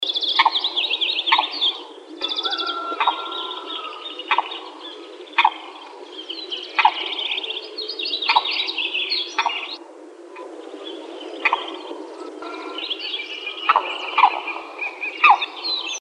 Limpkin (Aramus guarauna)
Province / Department: Entre Ríos
Location or protected area: Villa Paranacito
Condition: Wild
Certainty: Observed, Recorded vocal